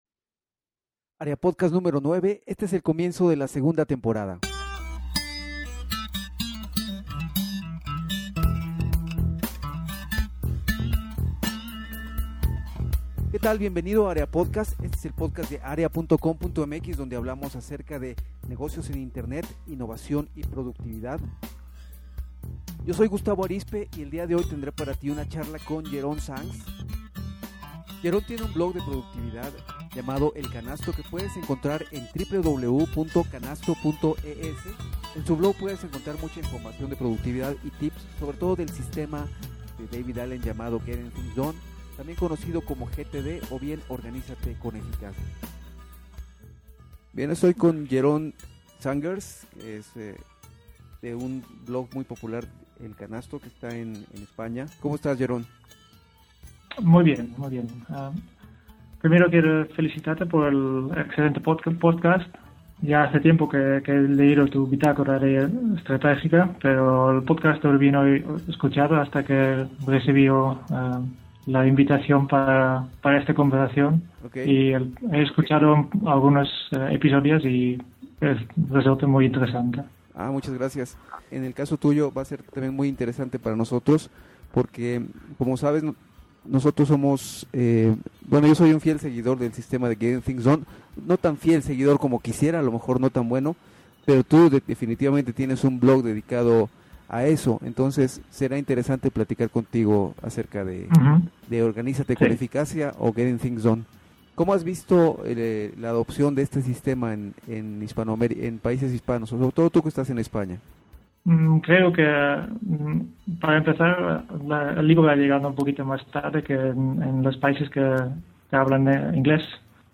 Charla